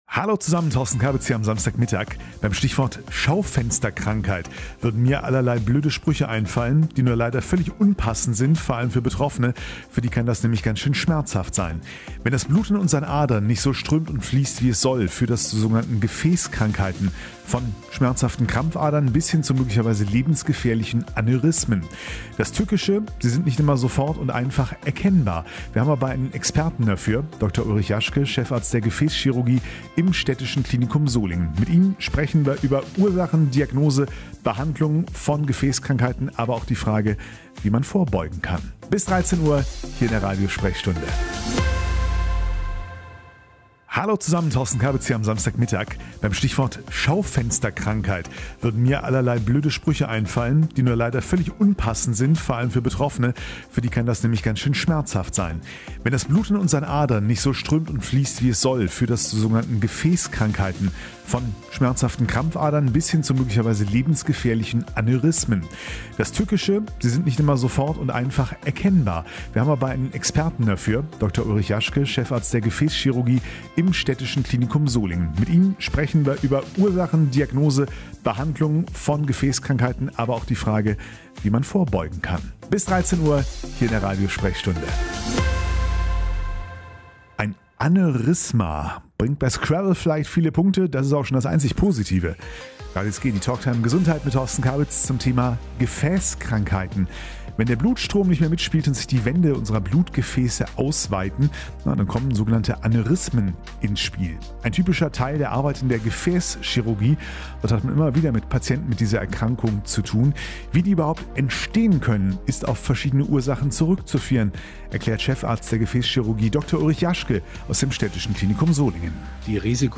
Von Krampfadern bis Aneurysmen - unser Thema in der Radiosprechstunde am 27. September.
Die Sendung zum Nachhören